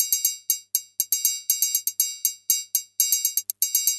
storm_triloop1.mp3